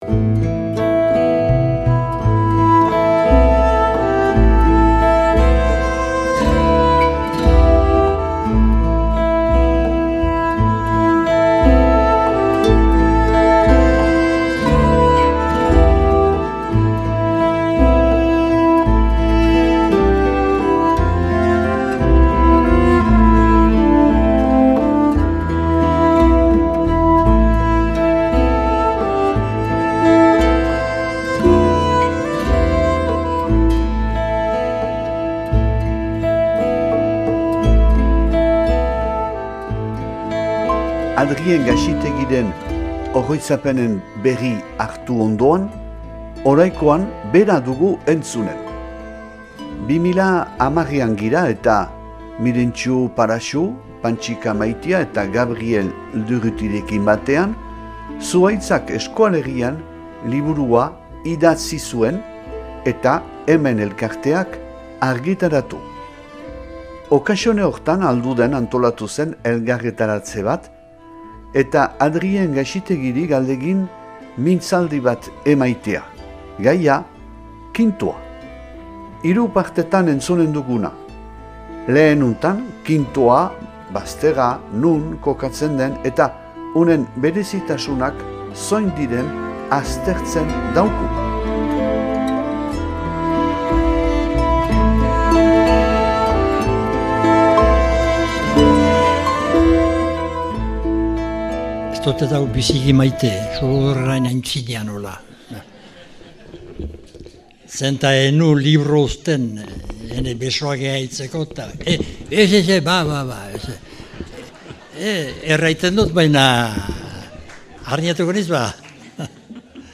irakurketa bat